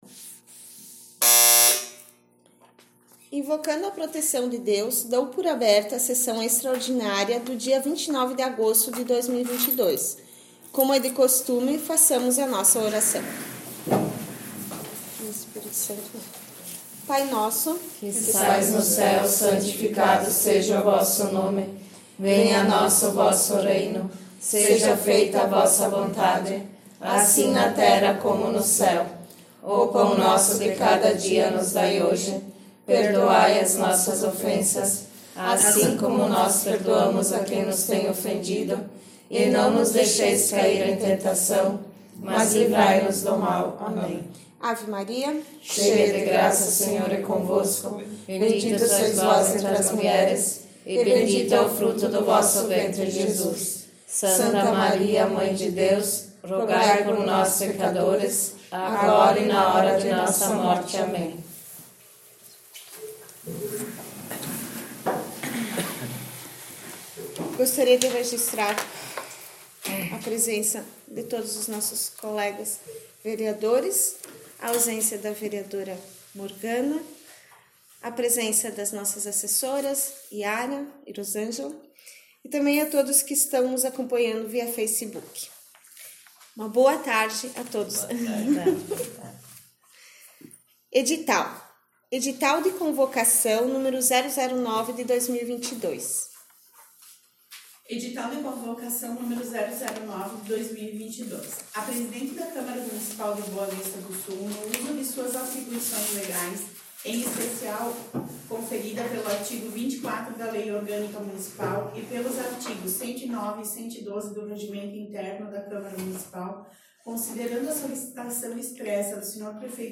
25 - Sessão Extraordinária 29 de ago 18.00.mp3